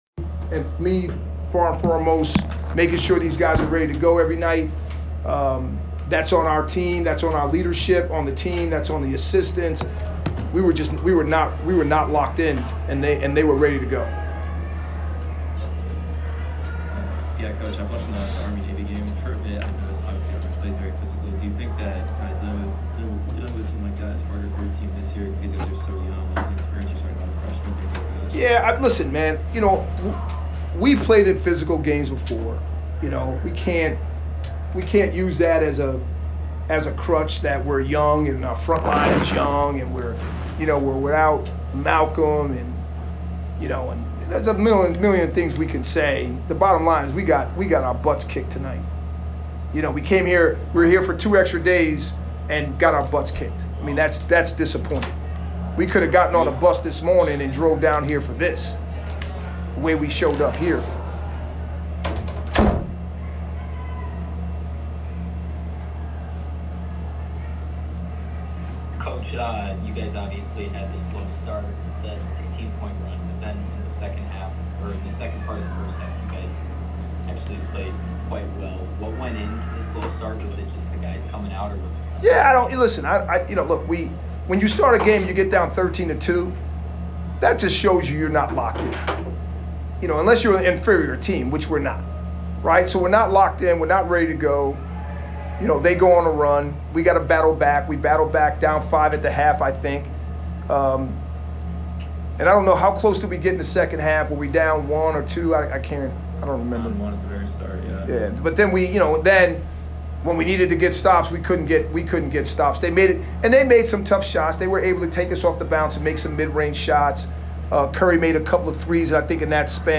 Army Postgame Interview